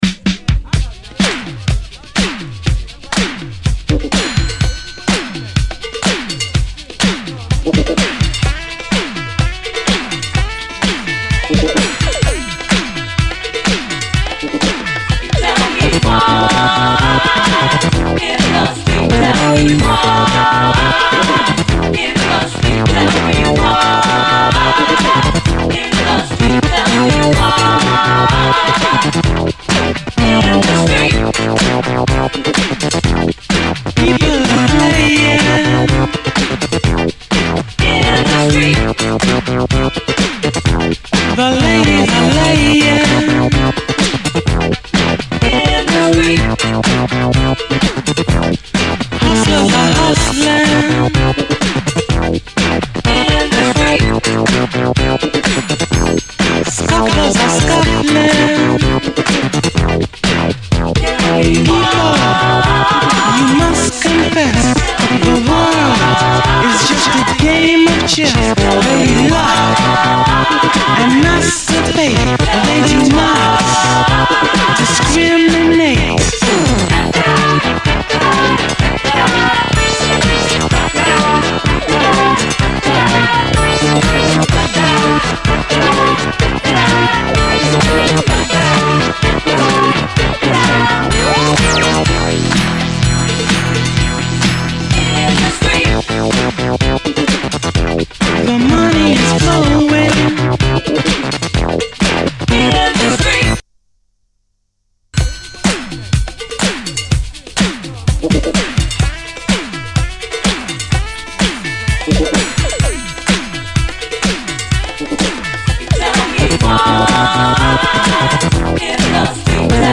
SOUL, 70's～ SOUL, DISCO
ギラギラとシンセが強烈にうねるUS産80’Sシンセ・ブギー・ファンク！西海岸〜ローライダー的ブギー・ファンク！
ギッラギラの強力ファンク・サウンドは勿論、女性コーラスもまたカッコいい。